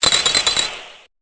Cri de Sorbébé dans Pokémon Épée et Bouclier.